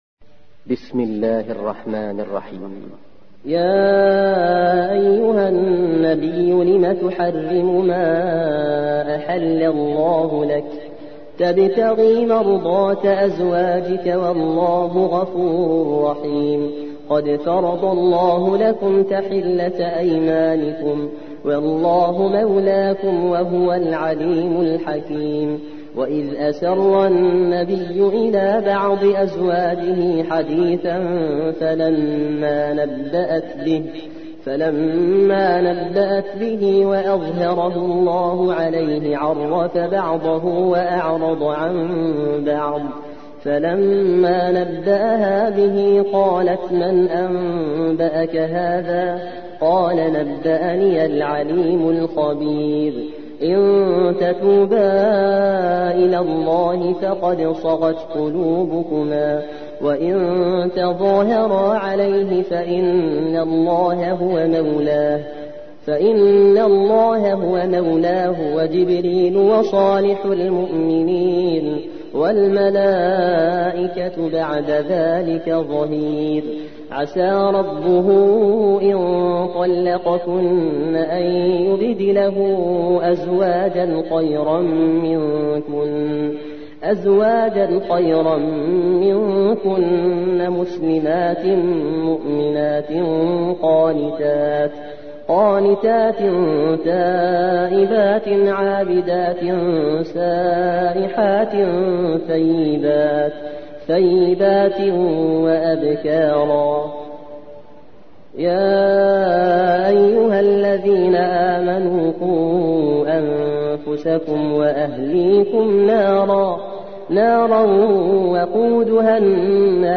66. سورة التحريم / القارئ